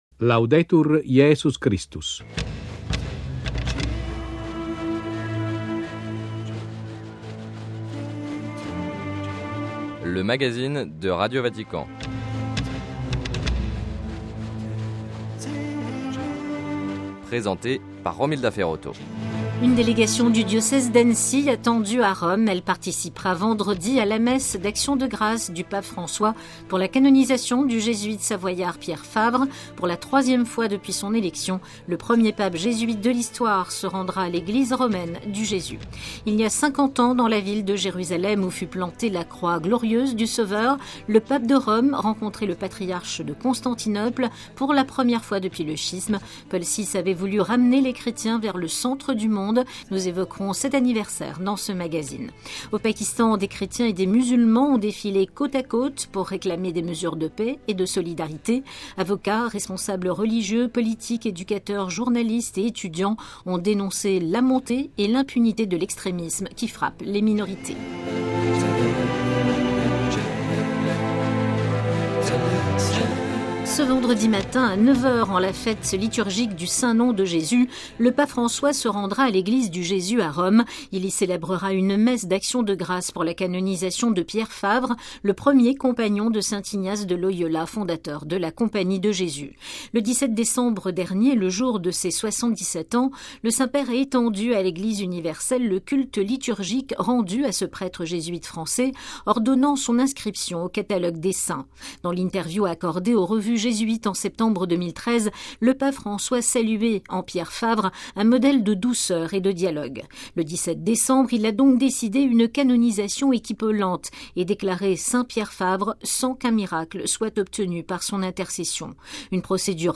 Entretien avec l'évêque d'Annecy qui concélèbrera avec le Pape François. - Les défis de la Compagnie de Jésus en Europe.